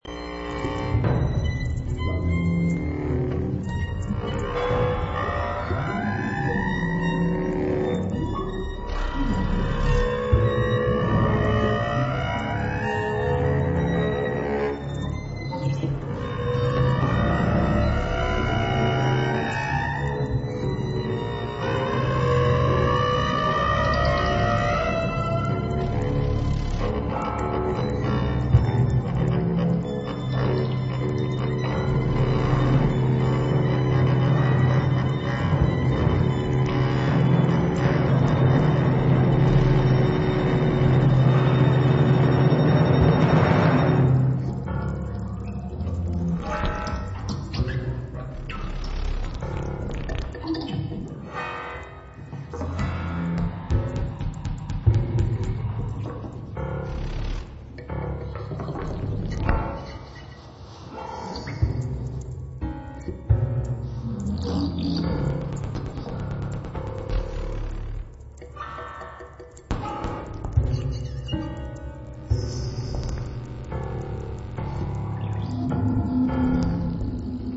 for 3 zithers and electronics
for organ and electronics